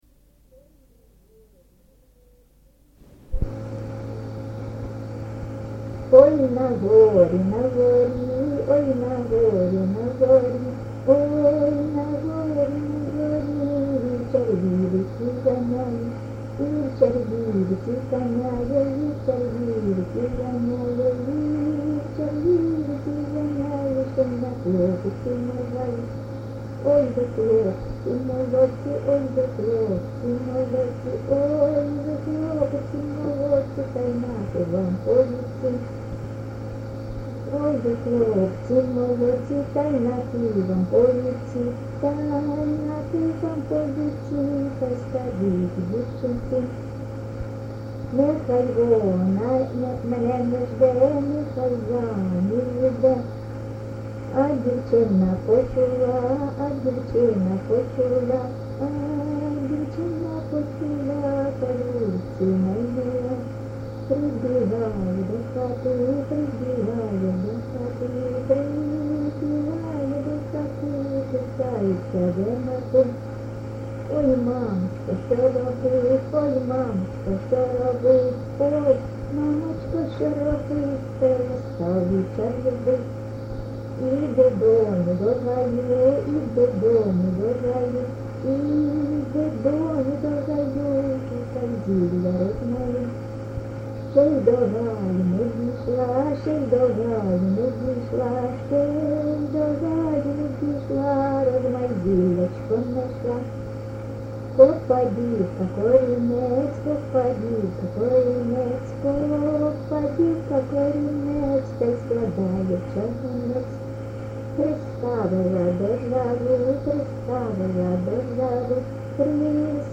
ЖанрПісні з особистого та родинного життя
Місце записус. Ярмолинці, Роменський район, Сумська обл., Україна, Слобожанщина